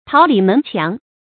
桃李門墻 注音： ㄊㄠˊ ㄌㄧˇ ㄇㄣˊ ㄑㄧㄤˊ 讀音讀法： 意思解釋： 謂生徒眾多的師門。